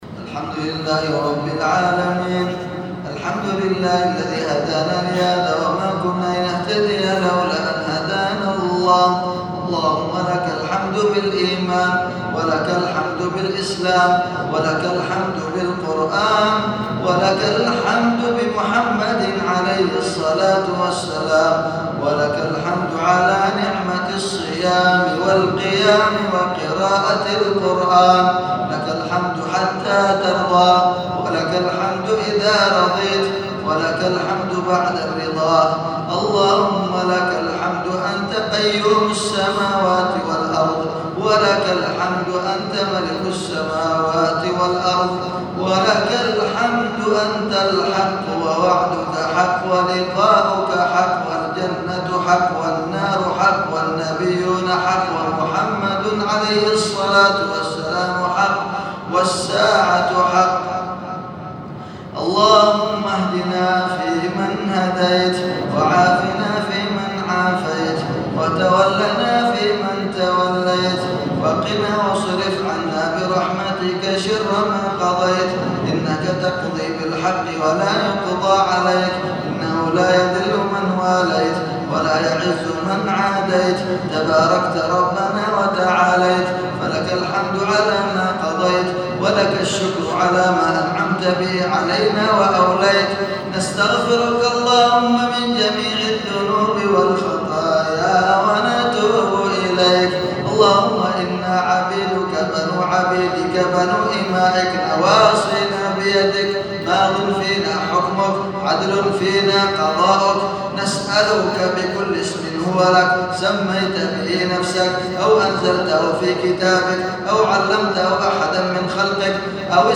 دعاء خاشع ومؤثر بصوت
من إحدى ليالي القيام.